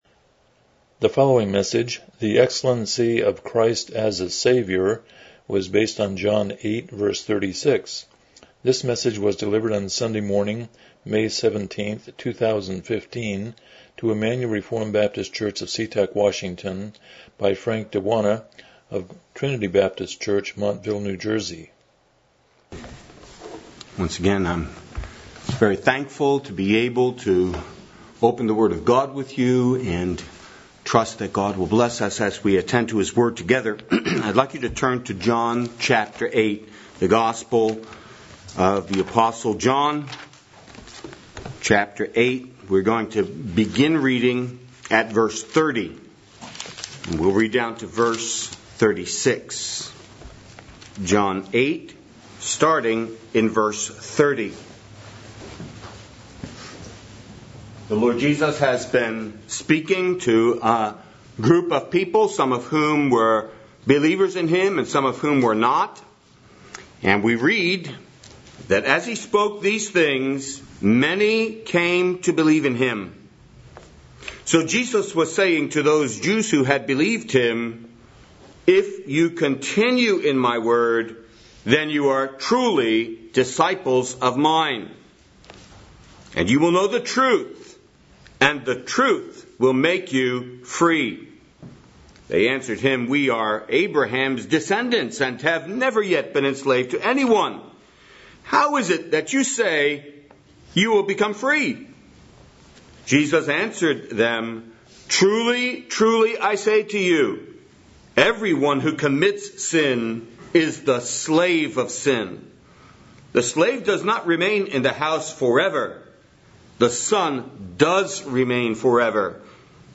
John 8:36 Service Type: Morning Worship « Fighting the Good Fight